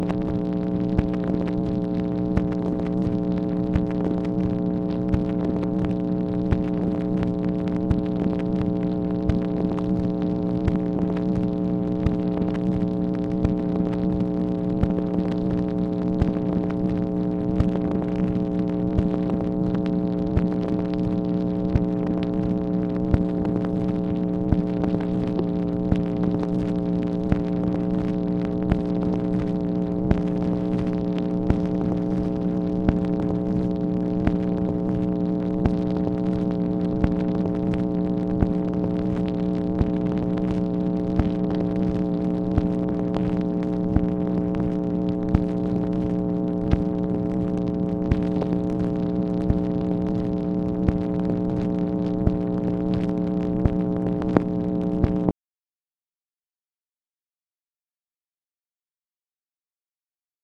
MACHINE NOISE, April 30, 1965
Secret White House Tapes | Lyndon B. Johnson Presidency